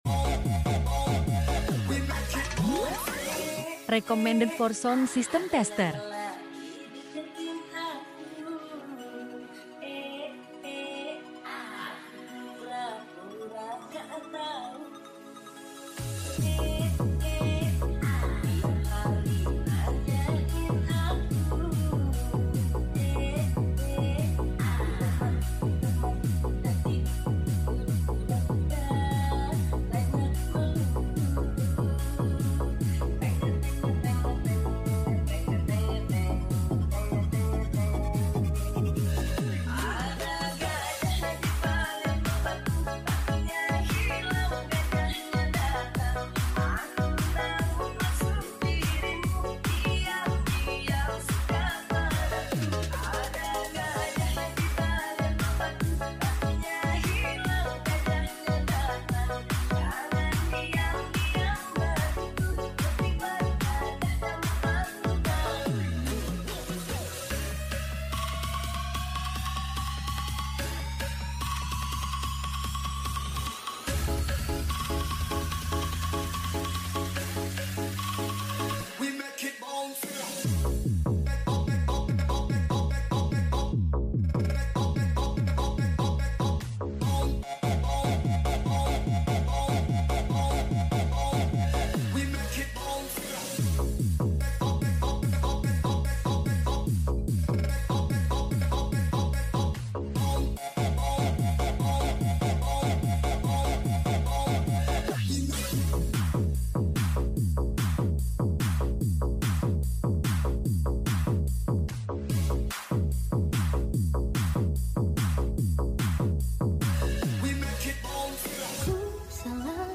Full Bass 10db